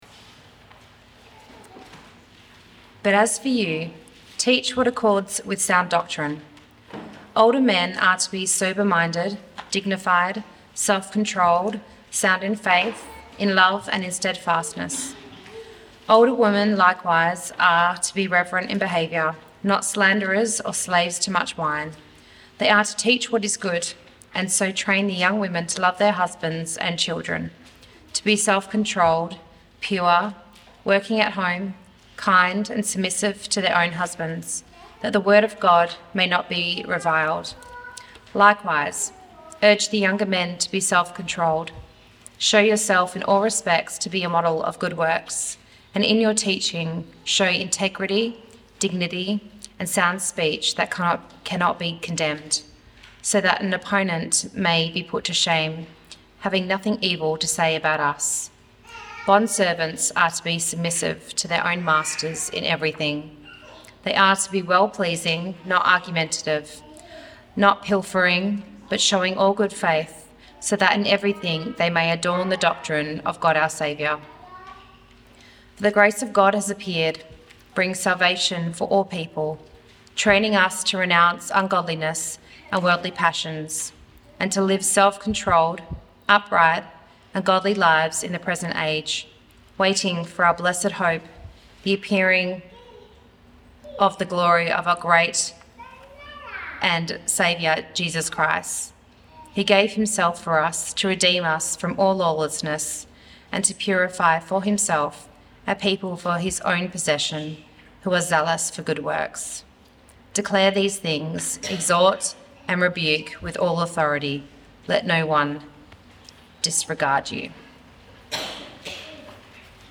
Camp Session 1-Titus 2:2-8.mp3